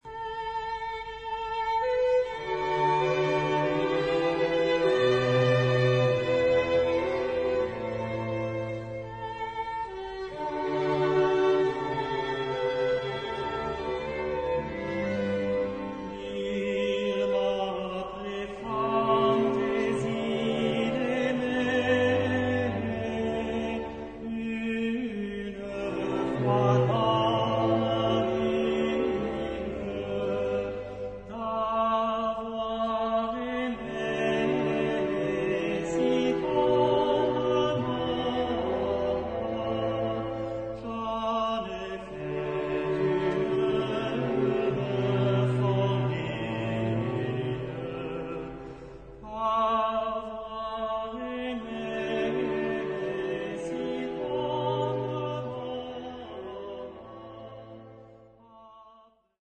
Genre-Style-Form: Secular ; Popular
Mood of the piece: sorrowful
Type of Choir: TBarB  (3 men voices )
Soloist(s): Baryton (1)  (1 soloist(s))
Tonality: G major